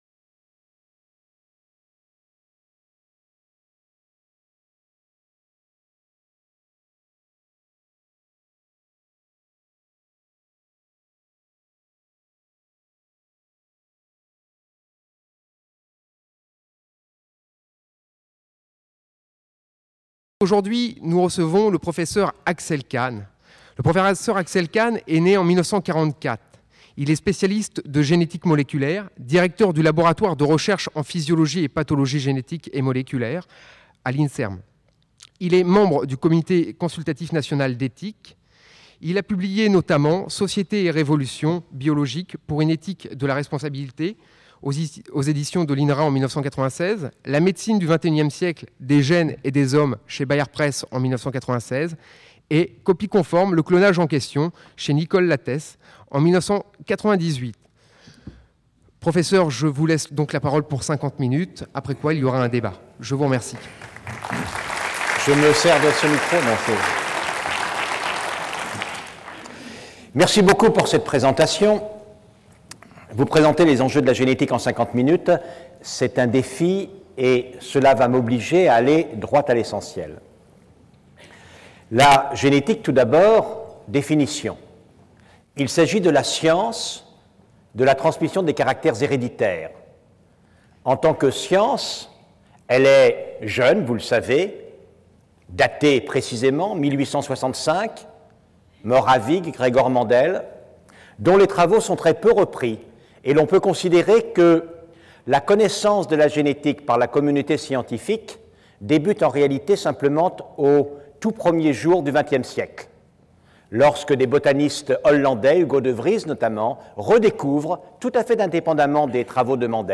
Conférence du 31 janvier 2000 par Axel Kahn.